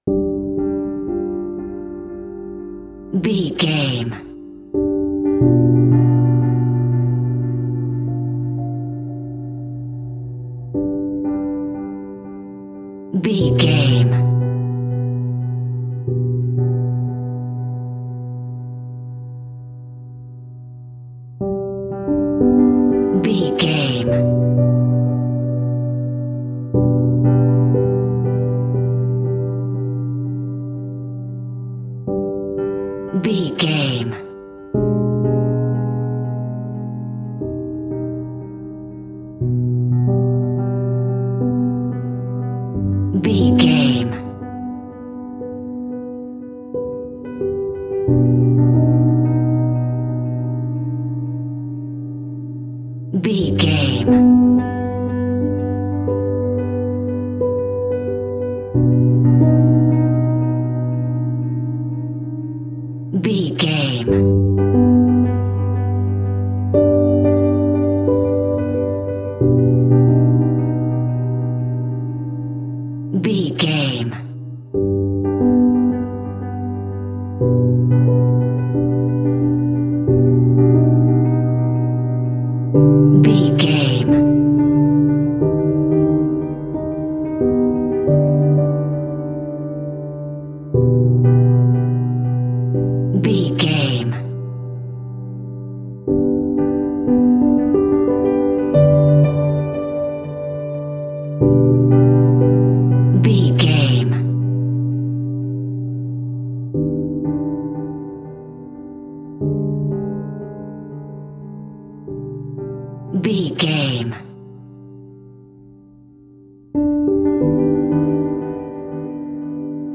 Piano Tripping.
Aeolian/Minor
tension
ominous
dark
haunting
eerie
instrumentals
horror music
horror piano